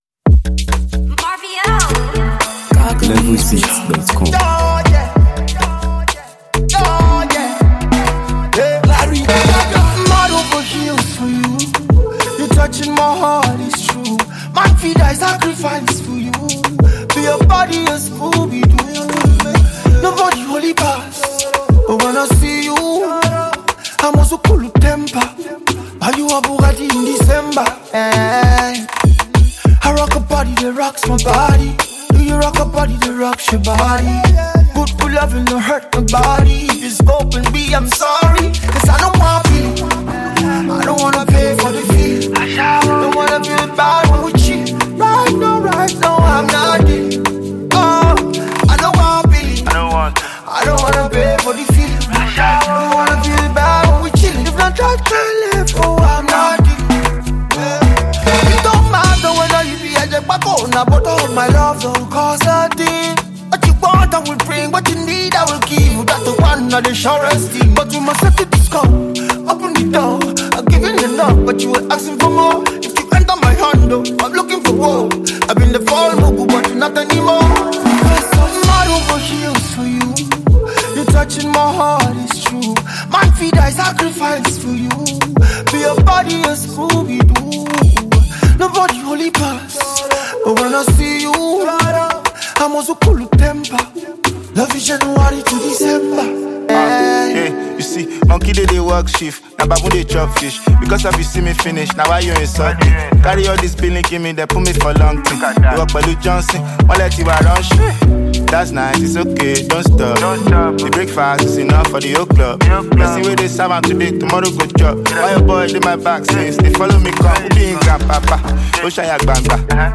seamlessly blends Afrobeats, rhythm, and thoughtful lyricism